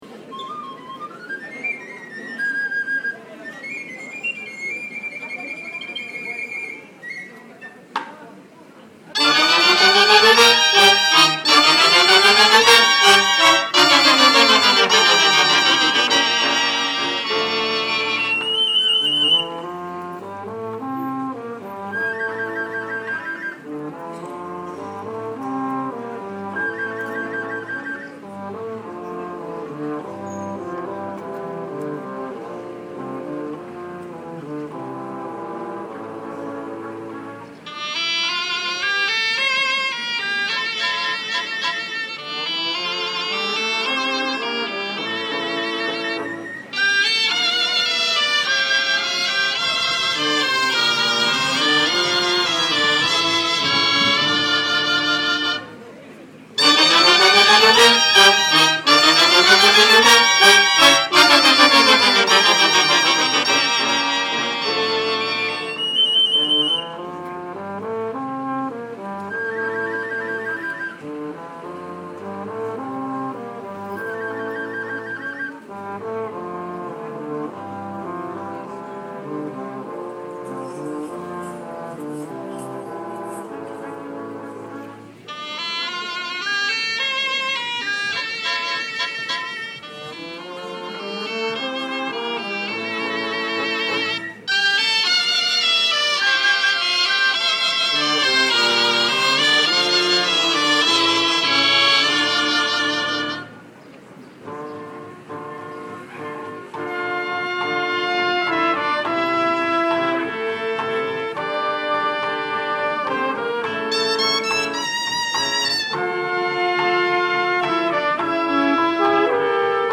Exemple de l’influencia del Puigferrisme